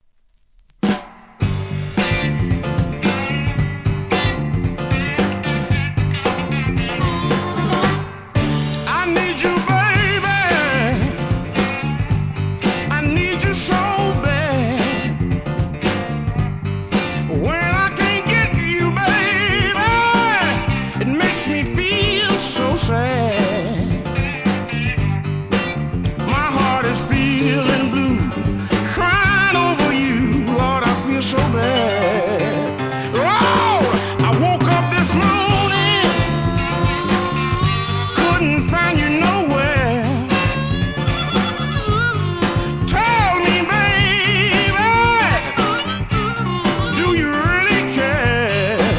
¥ 880 税込 関連カテゴリ SOUL/FUNK/etc...